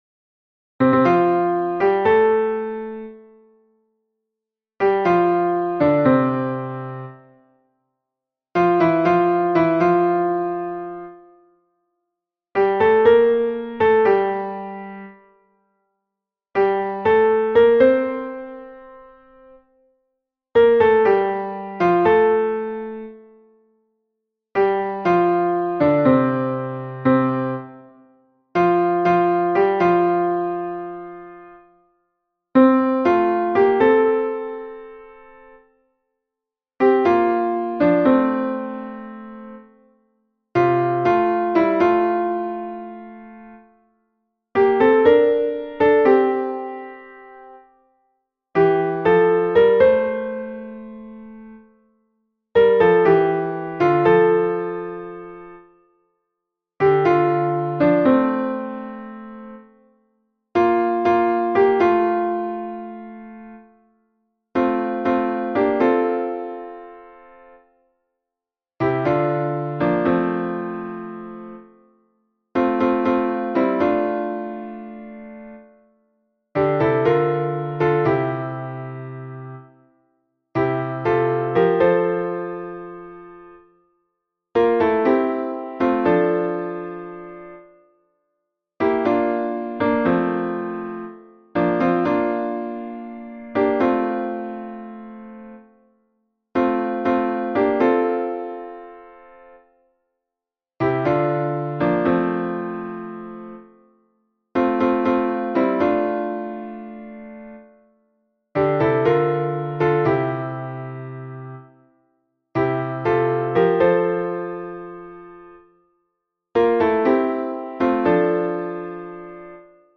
MP3 version piano
Piano